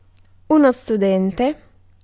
Ääntäminen
IPA: [e.lɛv]